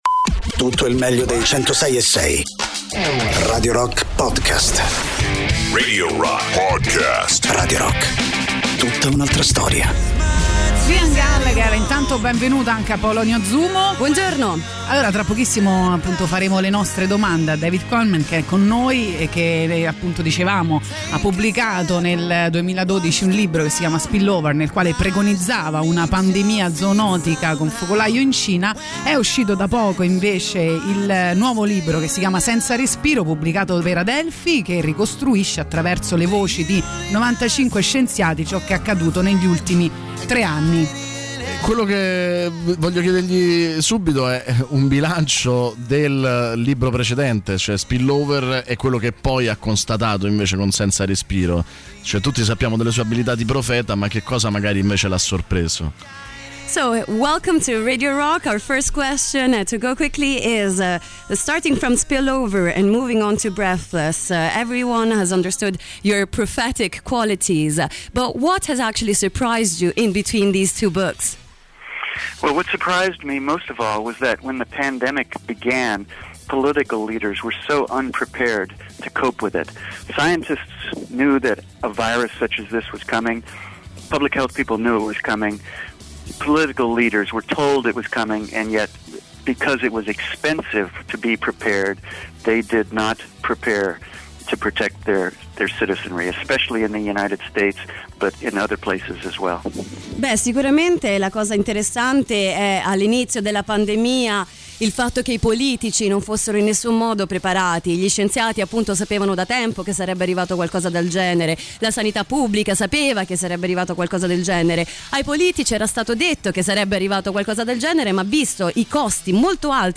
Interviste: David Quammen (24-11-22)